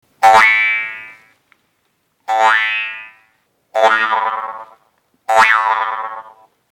Funny Spring Sound
Category ⚡ Sound Effects